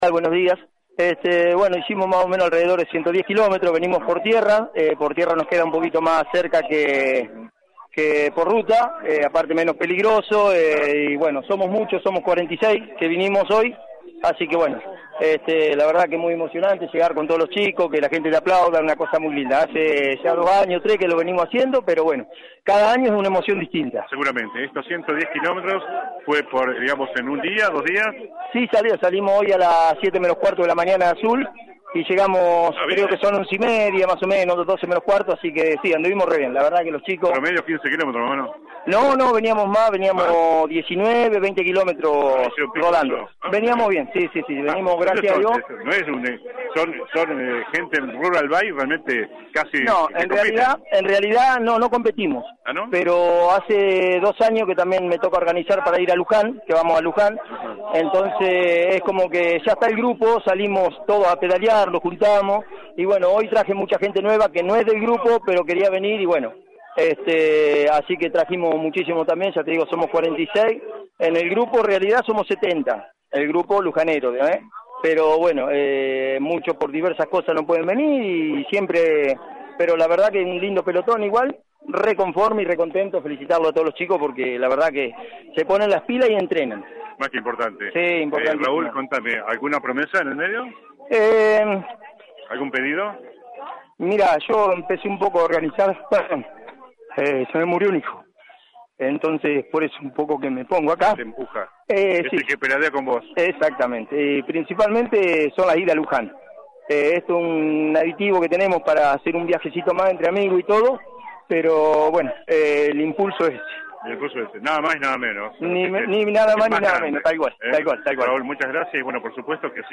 (galería de imágenes) La 91.5 estuvo este viernes en la ciudad serrana que está recibiendo a miles de turistas con motivo del feriado de Semana Santa.